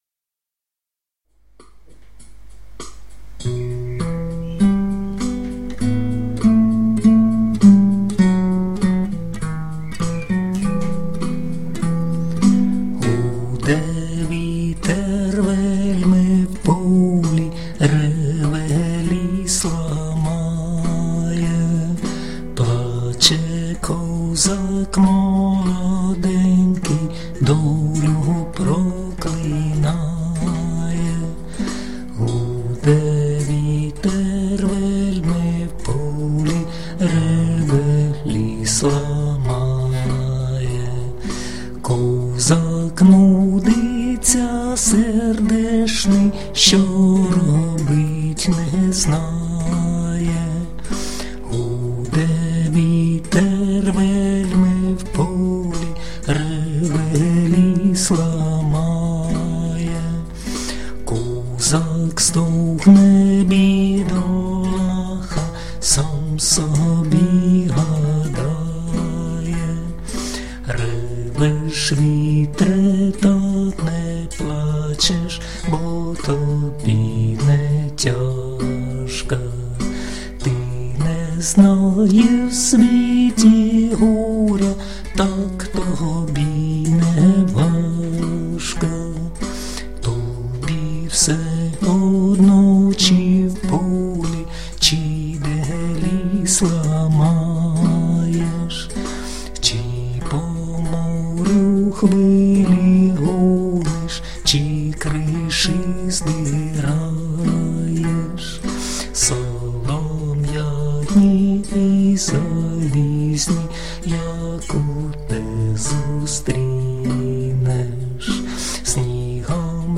../icons/davayseg.jpg   Українська народна пiсня